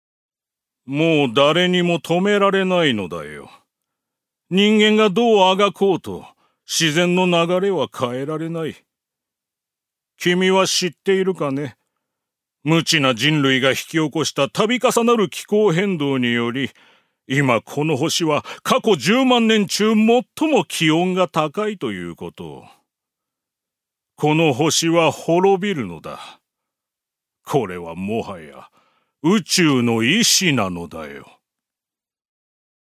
所属：男性タレント
セリフ２